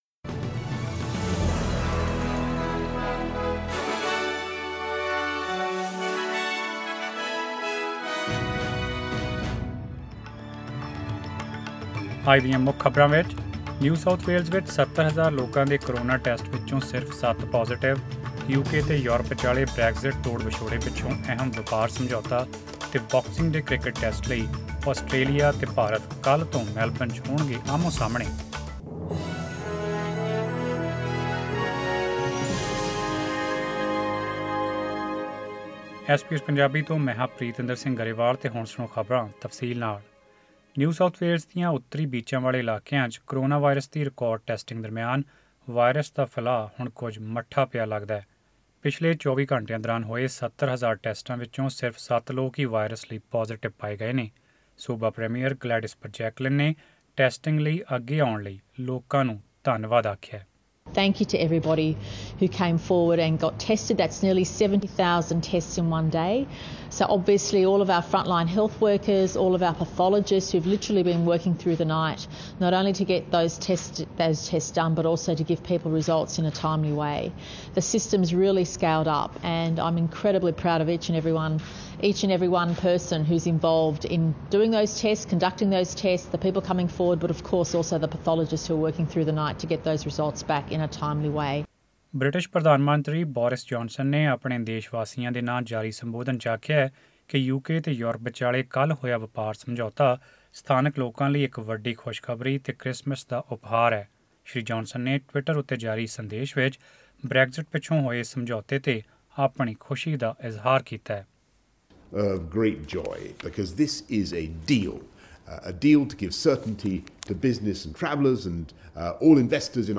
Click the audio icon on the photo above to listen to the full bulletin in Punjabi